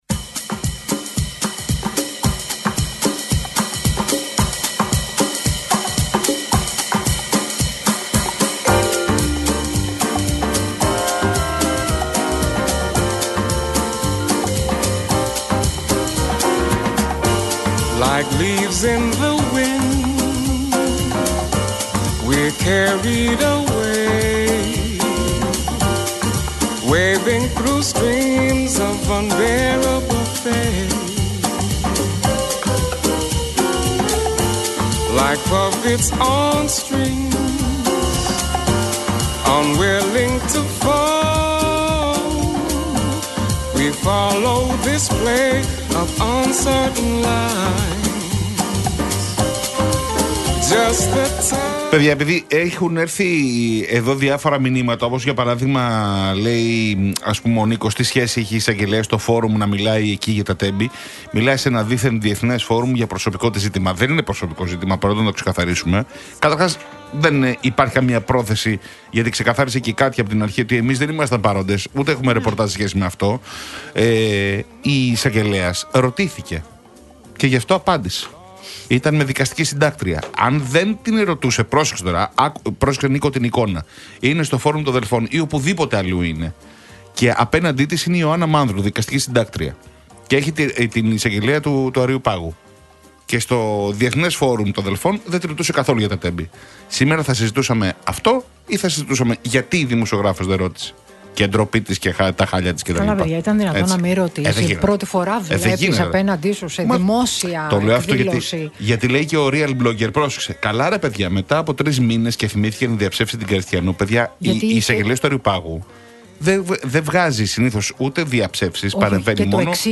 έδωσε ο υπουργός Επικρατείας Άκης Σκέρτσος μιλώντας στον Real FM 97,8